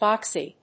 /ˈbɑksi(米国英語), ˈbɑ:ksi:(英国英語)/